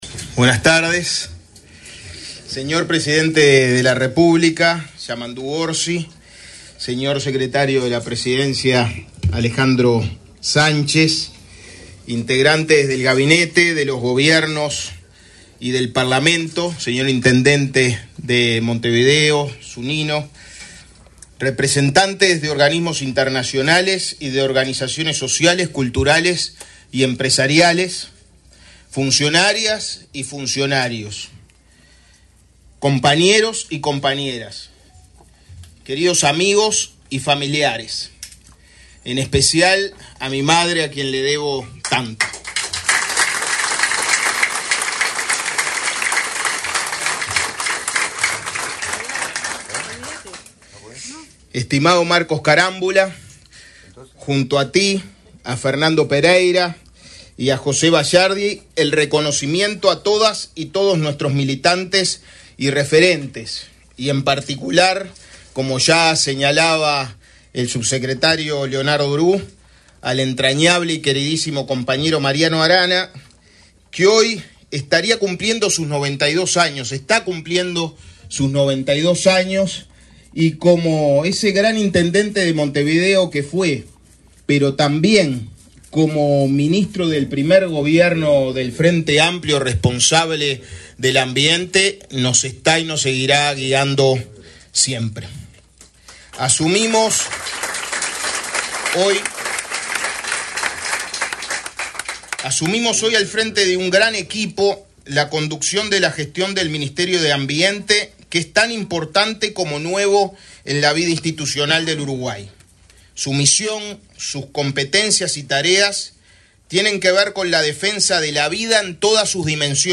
Palabras del ministro de Ambiente, Edgardo Ortuño
Palabras del ministro de Ambiente, Edgardo Ortuño 06/03/2025 Compartir Facebook X Copiar enlace WhatsApp LinkedIn El presidente de la República, profesor Yamandú Orsi, y la vicepresidenta, Carolina Cosse, participaron, este 6 de marzo, en la asunción de las autoridades del Ministerio de Ambiente. En la oportunidad, el ministro Edgardo Ortuño realizó declaraciones.